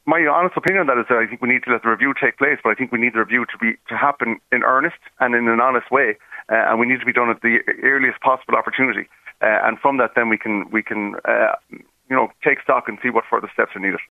Fianna Fail Galway West TD John Connolly, says a decision on his future can wait until the party’s presidential election performance review is completed…………..